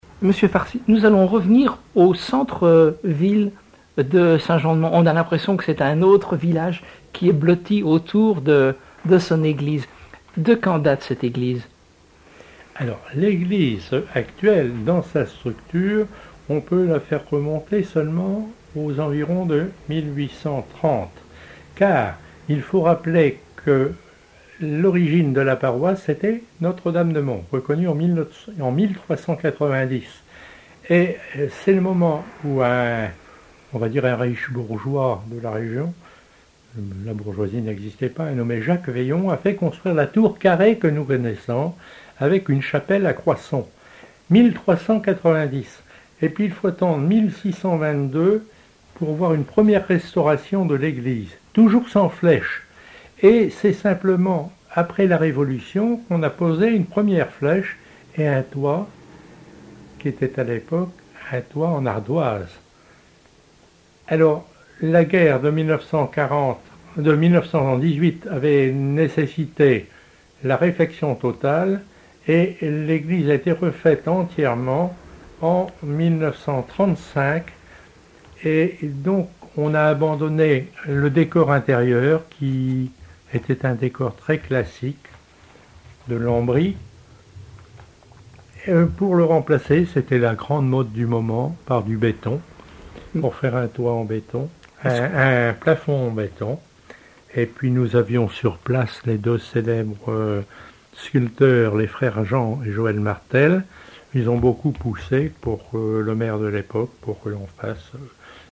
Emissions de la radio RCF Vendée
Catégorie Témoignage